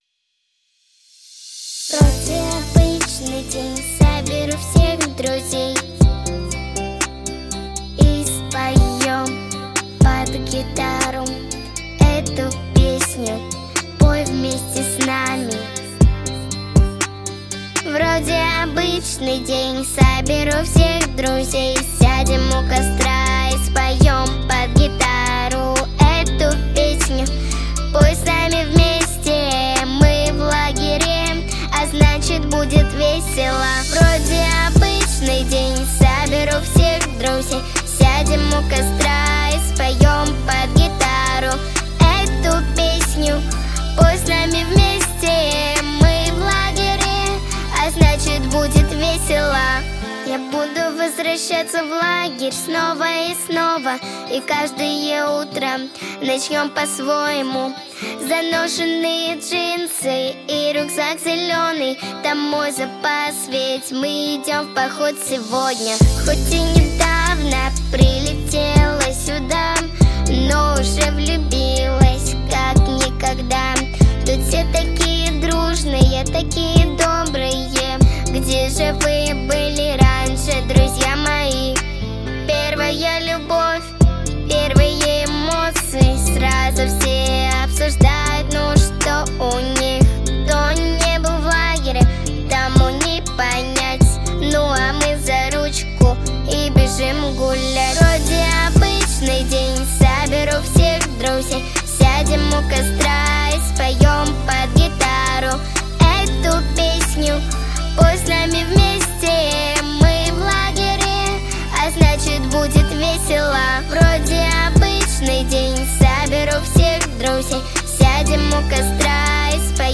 • Категория: Детские песни
Вожатские песни